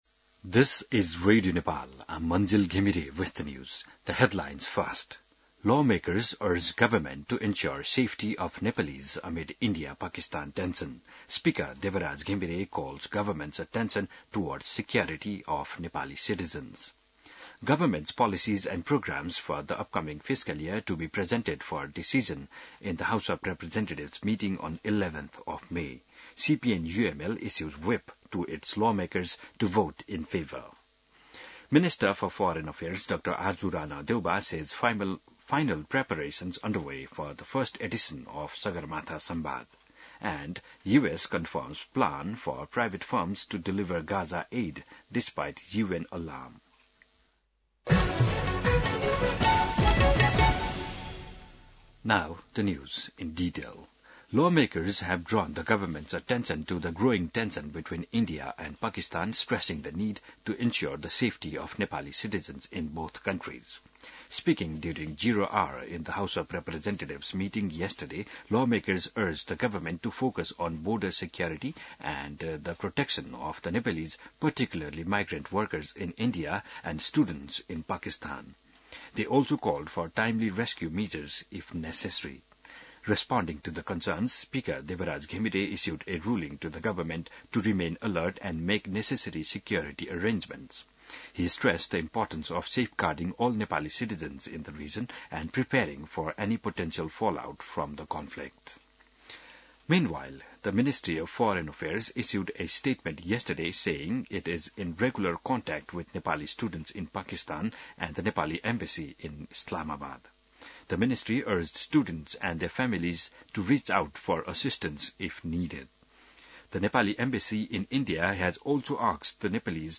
बिहान ८ बजेको अङ्ग्रेजी समाचार : २७ वैशाख , २०८२